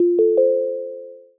accomplished alert amazing application awesome bells blip bonus sound effect free sound royalty free Animals